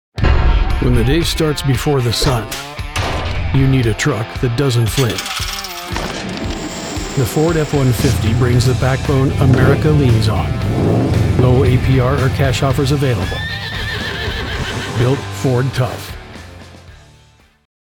Gritty, Rugged, American Voiceover
• Gritty, masculine tone with Western authenticity
• Cinematic pacing perfect for national TV and radio
Truck Commercial Voiceover Demos
• Fully treated booth